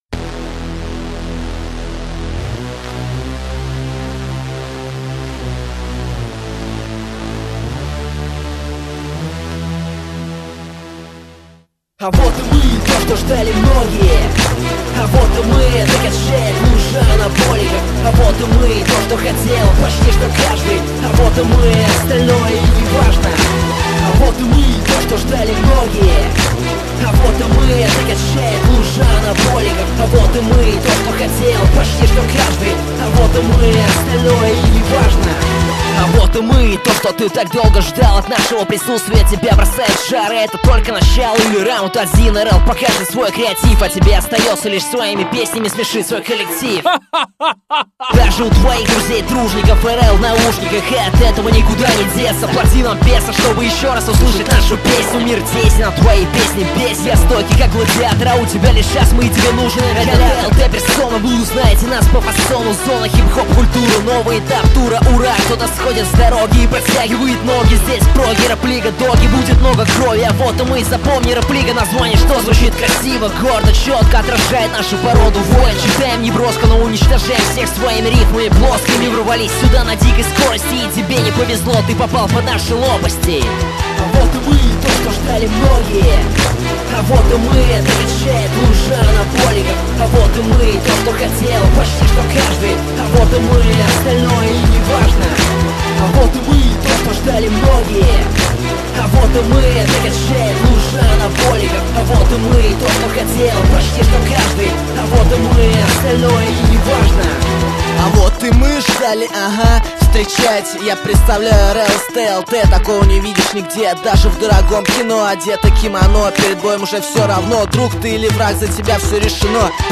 рэп группы